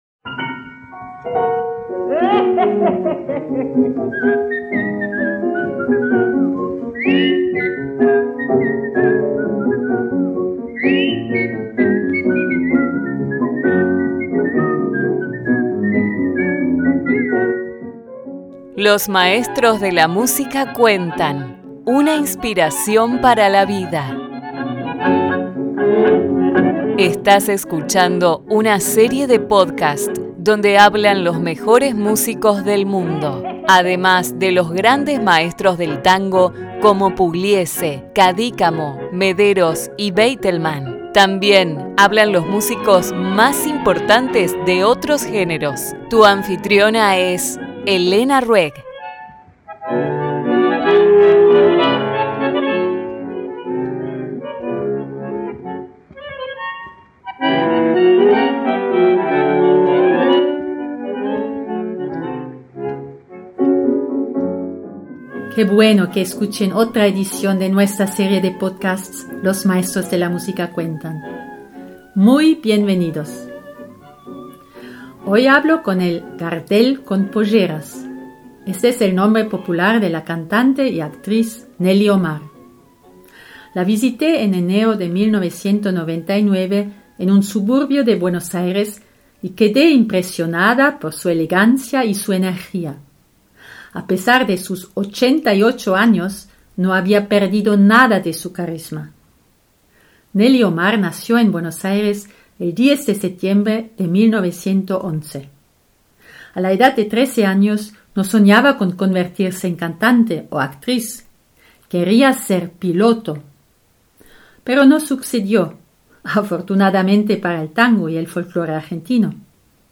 Nelly Omar, la entrevista - transcripción y revista